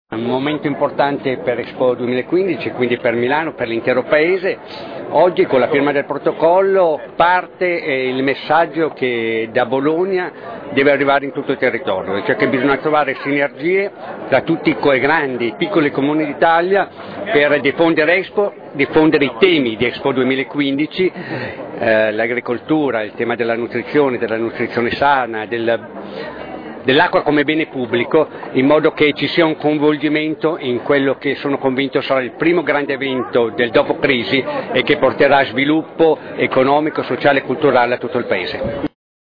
Ascolta Giuliano Pisapia